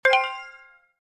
Download Purchase sound effect for free.